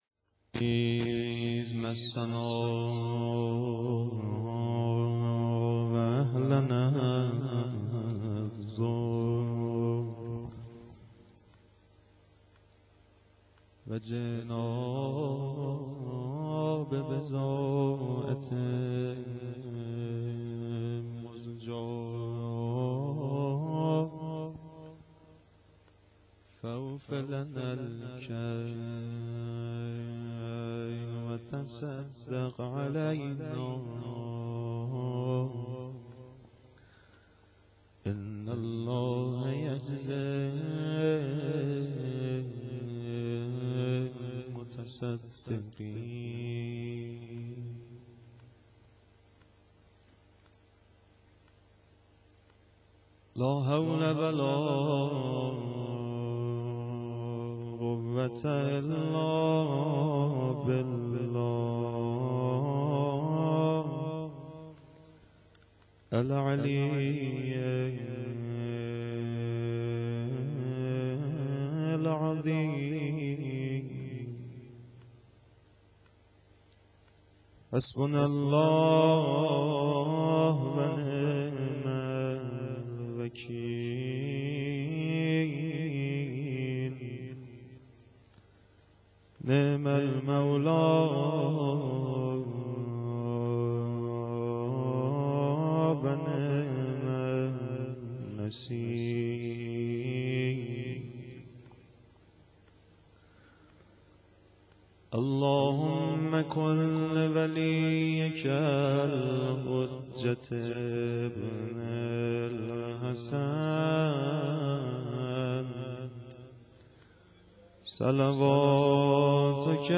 شعرخوانی
شب 21 رمضان 92